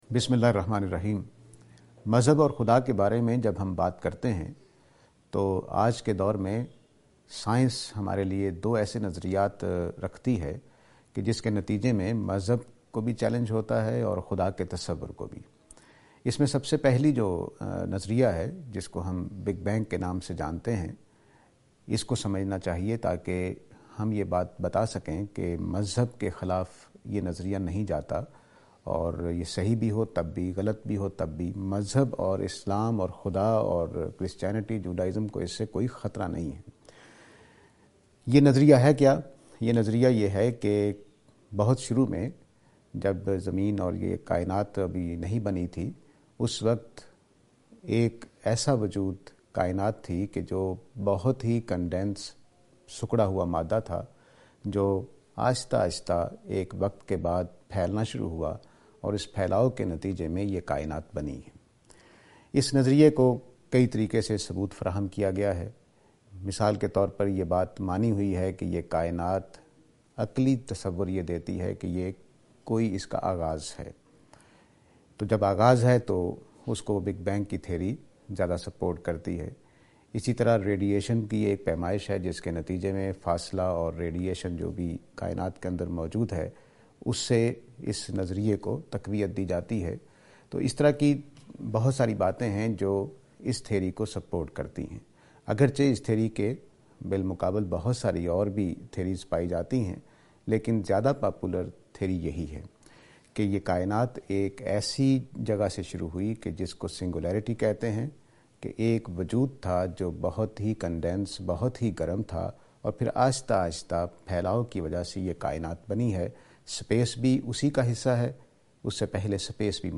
This lecture is and attempt to answer the question "Science and God: Big Bang".